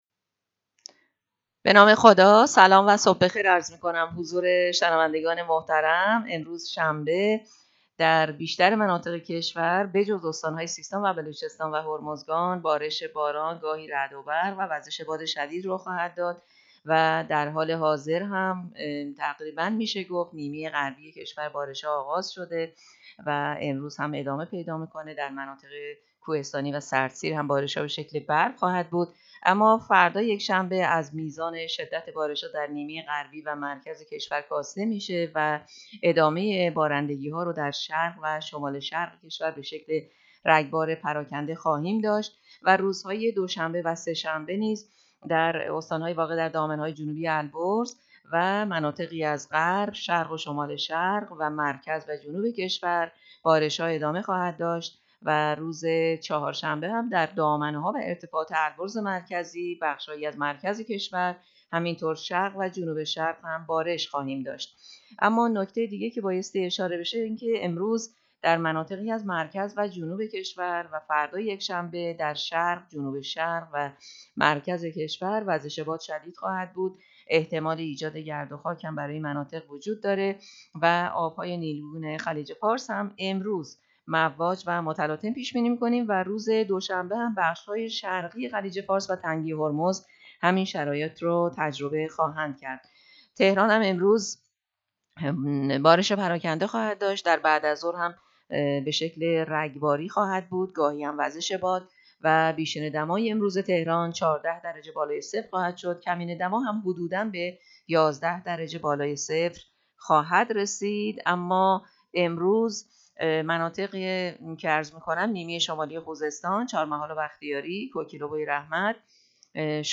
گزارش رادیو اینترنتی پایگاه‌ خبری از آخرین وضعیت آب‌وهوای۱۸ اسفند؛